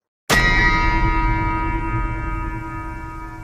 rdr2 low honour sound Meme Sound Effect
rdr2 low honour sound.mp3